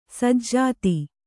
♪ sajjāti